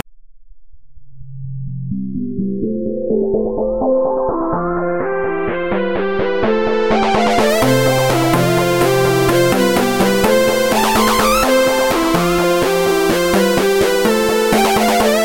电子合成器介绍
描述：幸福的氛围 :)可能是大的。
Tag: 126 bpm Chill Out Loops Synth Loops 2.57 MB wav Key : Unknown